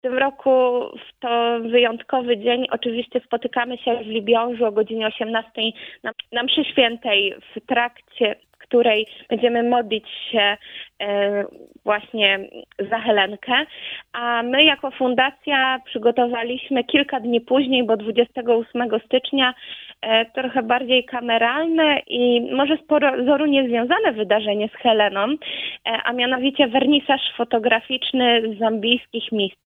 Łączymy się telefonicznie